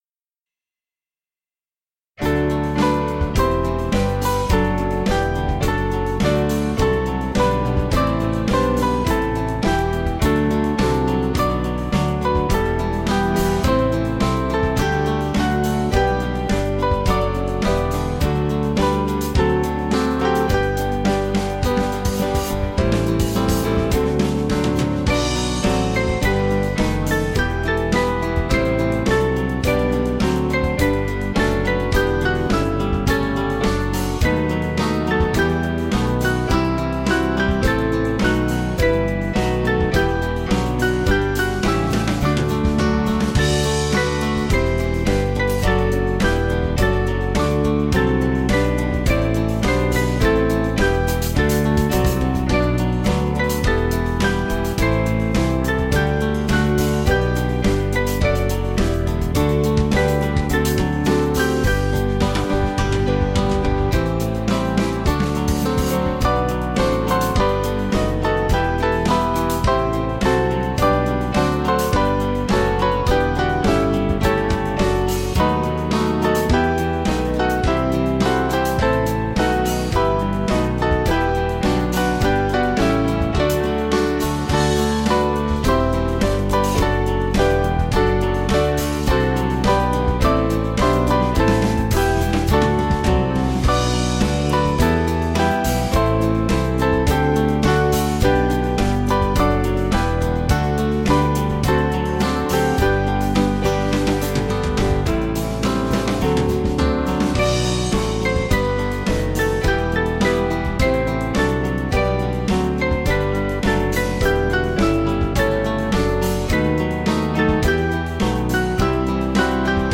8.7.8.7.D
Small Band